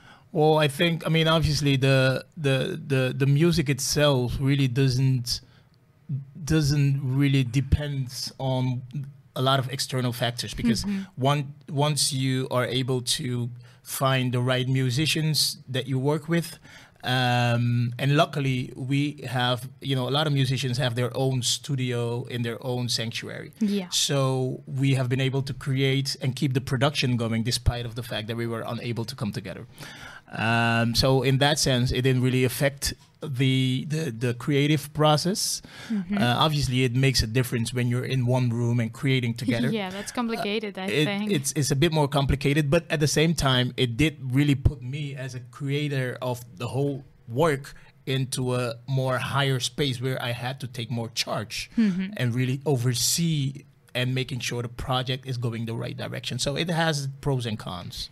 Però abans que sonin les primeres notes del seu nou treball, ‘Soul Revolution’, hem tingut l’honor que visiti l’estudi de Ràdio Capital.